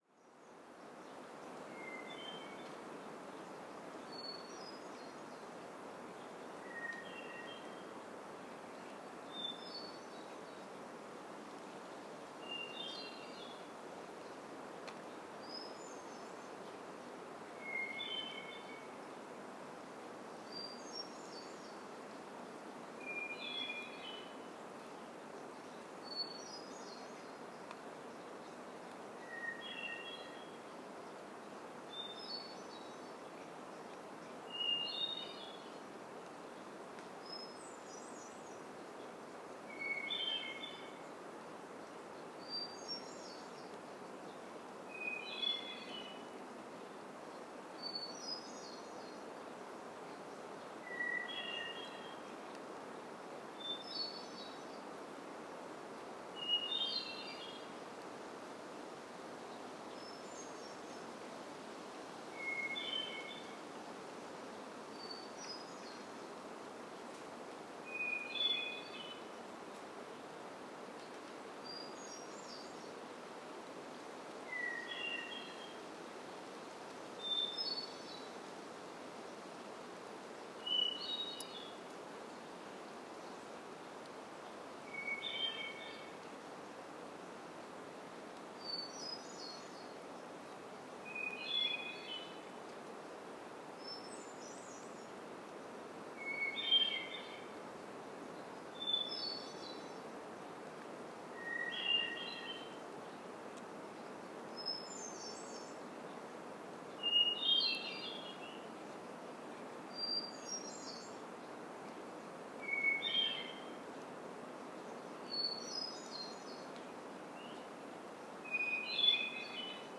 Hermit Thrush – Catharus guttatus
SAND DUNES OF TADOUSSAC – Dusk Chorus Recording of the forest at dusk. Species : Hermit Thrush and Swainson’s Thrush.